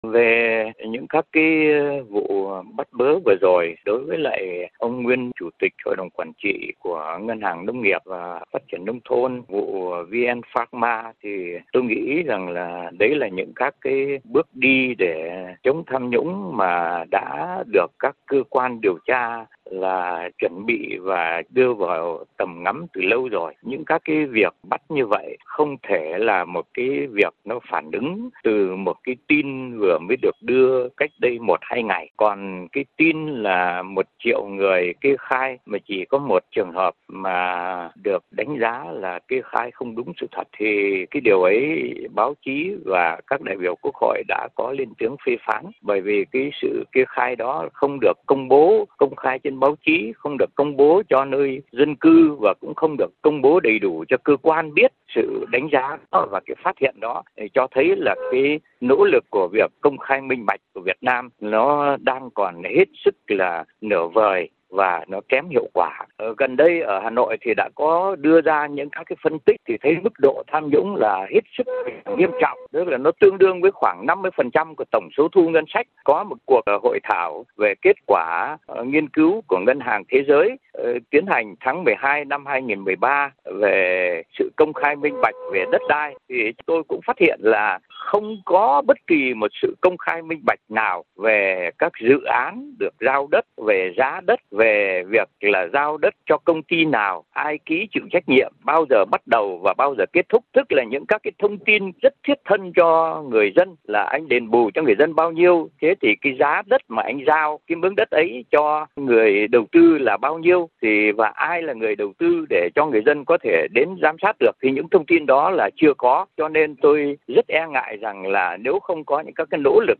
Sau đây mời quý vị nghe một vài nhận định của Tiến sĩ Lê Đăng Doanh, nguyên là Viện trưởng Viện Quản lý Kinh tế Trung ương, người dành nhiều chú ý cho cuộc chiến chống tham nhũng và vấn đề minh bạch thể chế tại Việt Nam.
Tải để nghe tiến sĩ Lê Đăng Doanh tại Hà Nội